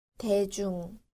• daejung